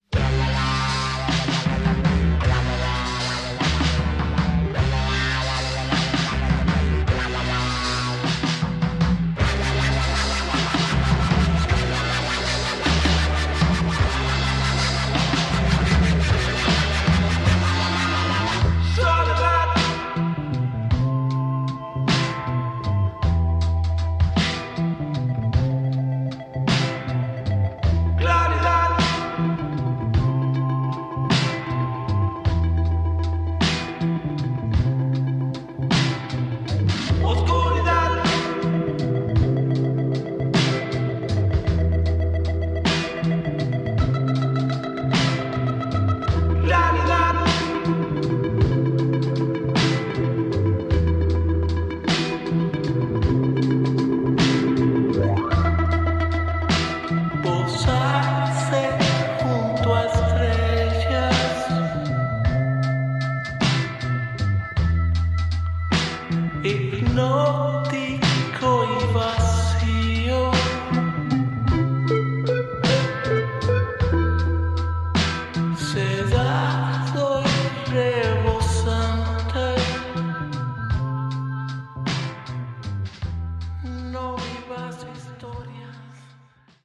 Tags: Psicodelico